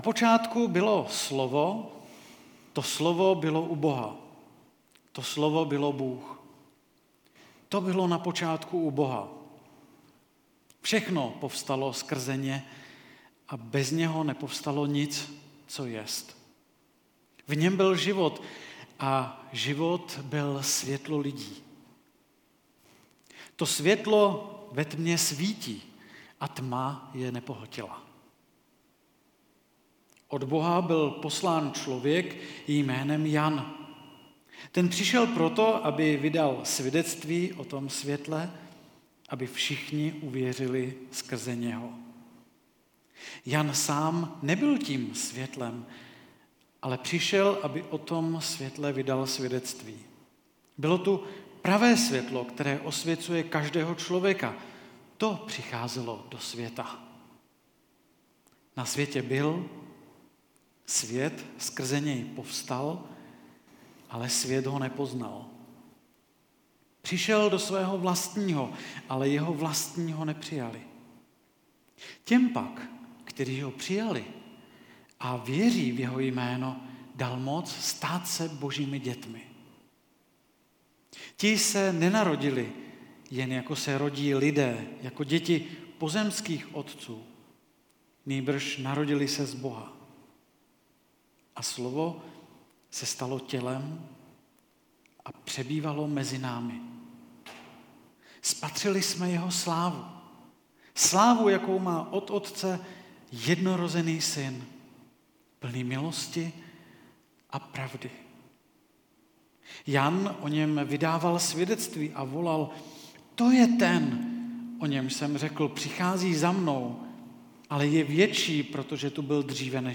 1. kázání ze série Záblesky slávy (Jan 1,1-18)
Kategorie: Nedělní bohoslužby